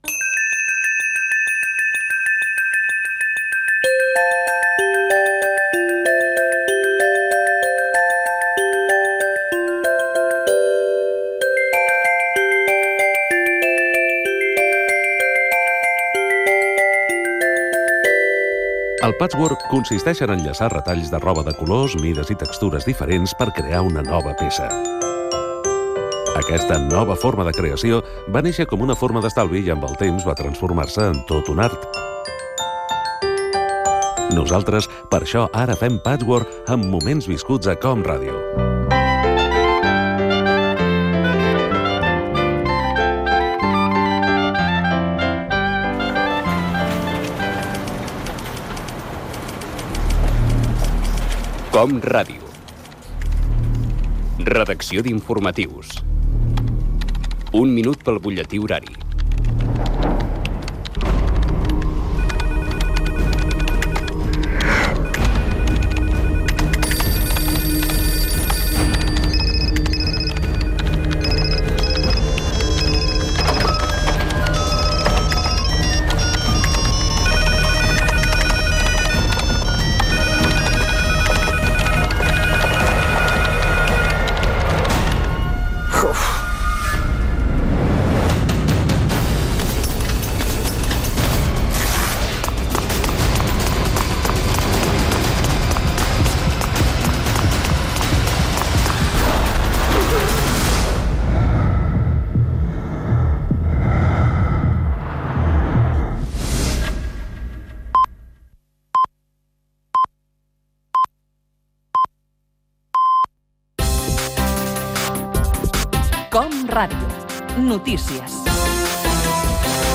Resum de diversos moments divertits de la programació de l’any 2008 de COM Ràdio
Entreteniment
Fragment extret de l'arxiu sonor de COM Ràdio.